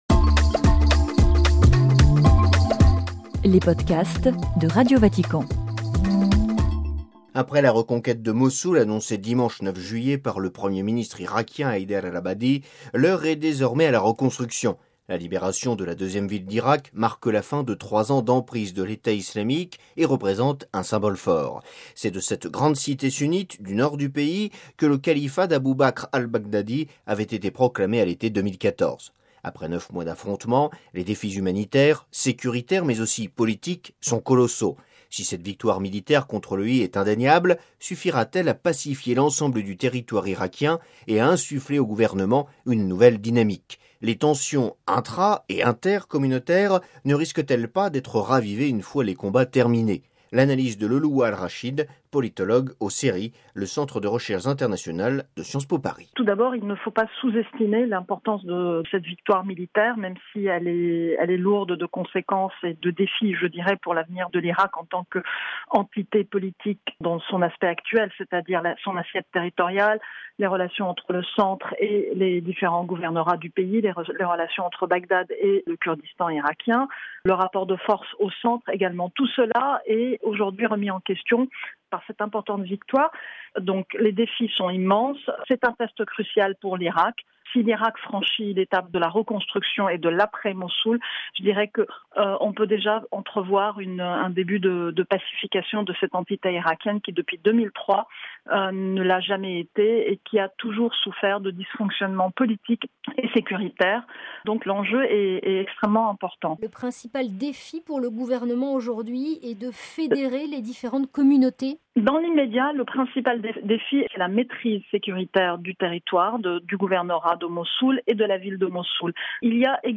(RV) Entretien - Après la reconquête de Mossoul annoncée dimanche 9 juillet 2017 par le Premier ministre irakien Haider al-Abadi, l’heure est désormais à la reconstruction.